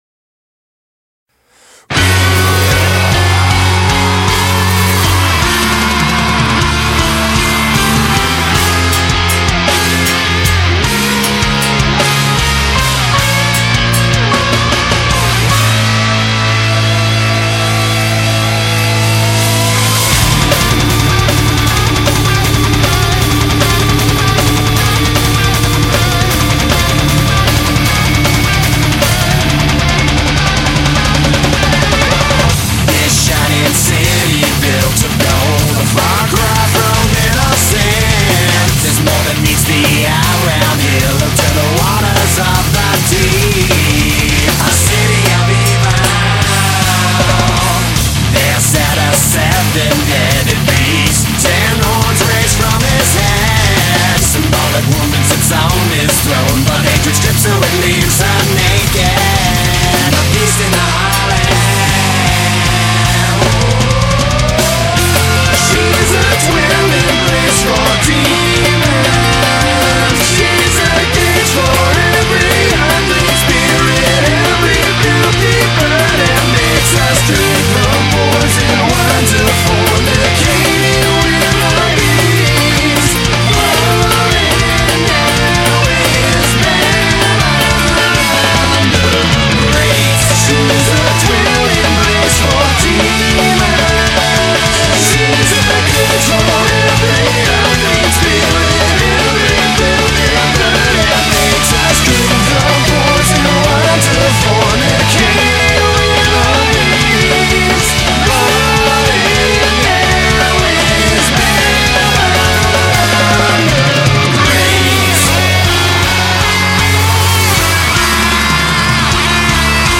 BPM155
Audio QualityPerfect (High Quality)